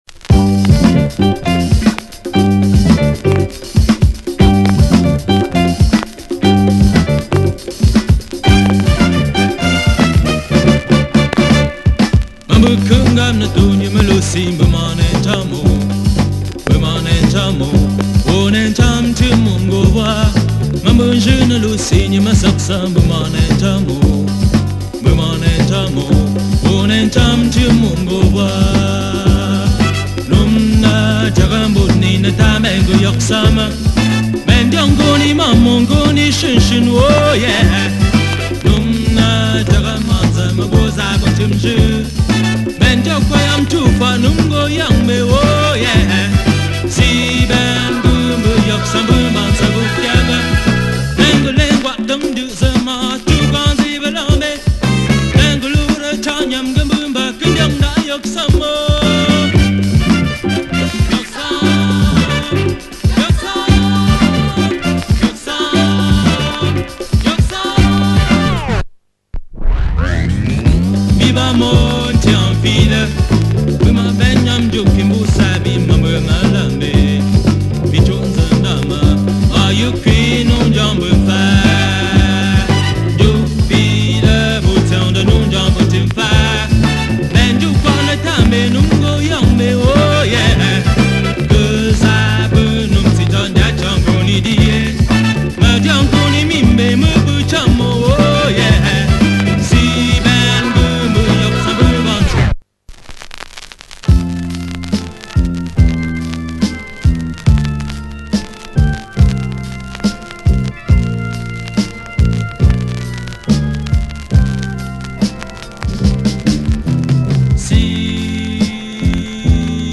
disco pop